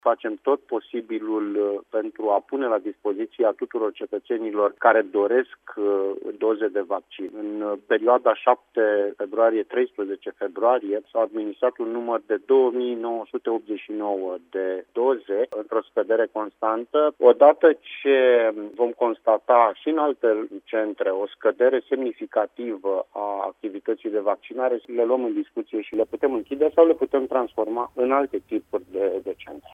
Centrele de vaccinare anti-COVID 19 din județul Timiș vor fi închise treptat, dacă populația nu manifestă interes pentru imunizare. Precizarea a fost făcută la Radio Timișoara de subprefectul Sorin Ionescu.